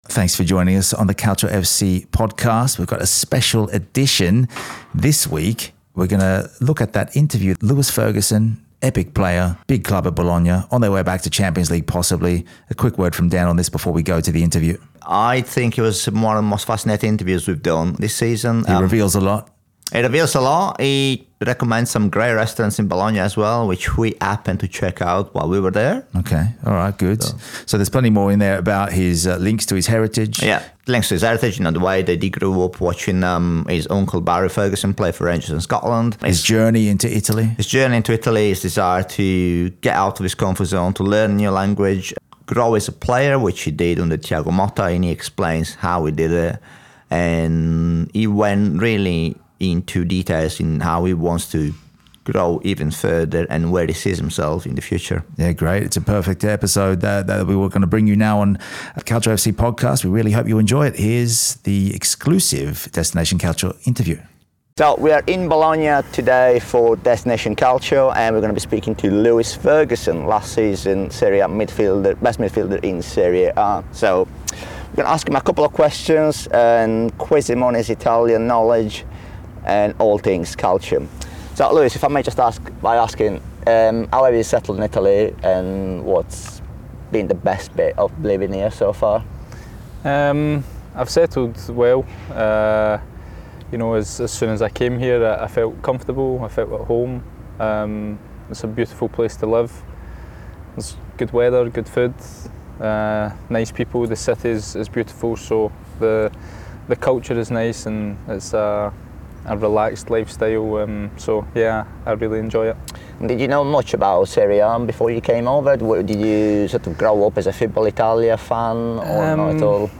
Lewis Ferguson Exclusive Interview | Scotland International & Bologna FC